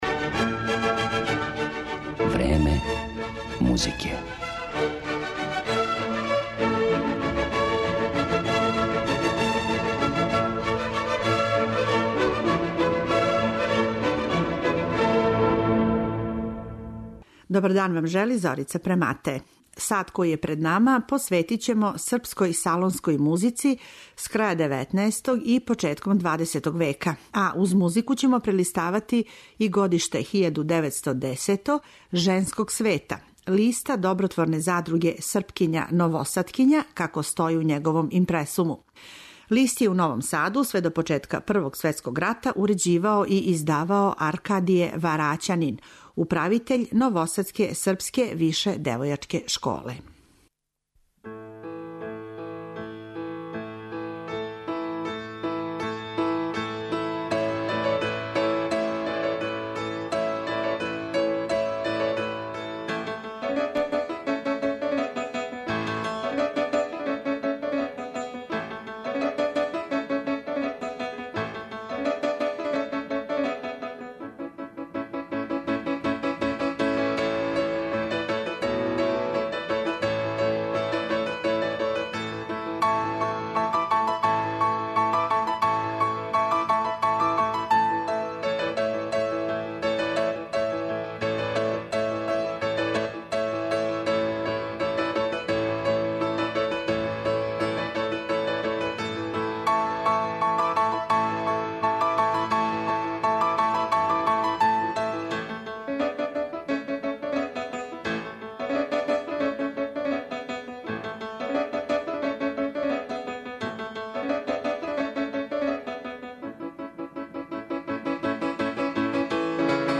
У емисији ћете чути и музику српског бидермајера, хорове, соло-песме и клавирске комаде Корнелија Станковића, Исидора Бајића и Станислава Биничког.